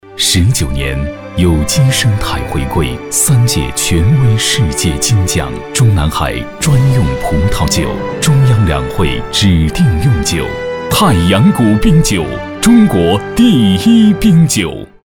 男国213_广告_酒类_大阳谷冰酒_浑厚.mp3